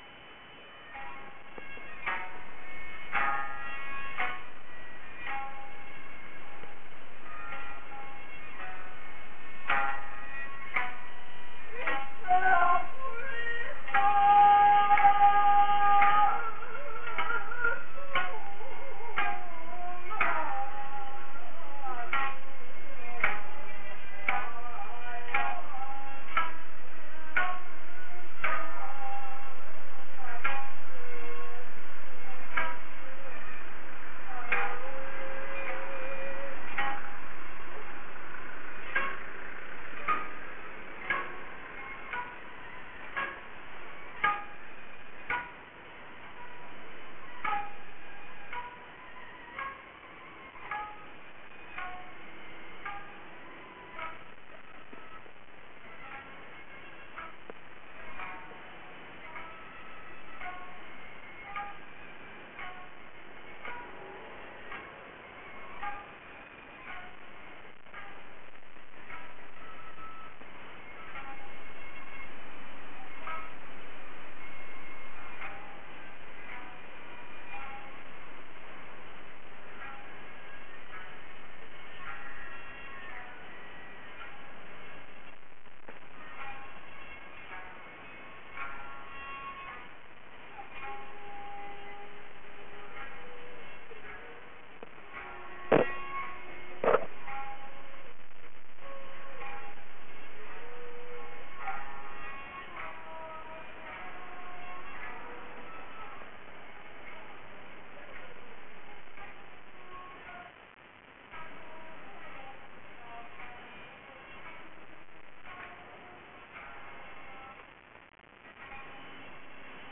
民謡「おわら」は三百年余の歴史を持ち、情緒豊かで気品が高い、哀調の中に優雅さがある。詩的な唄と踊りです。
毎年九月１、２、３日は、数千のぼんぼり、まん灯、まん幕で飾られ、老いも若きも男も女も、揃いのはっぴやゆかた姿に編笠をつけ、三味線、胡弓の音につれて唄い踊り、夜の白むまで町を流して行く。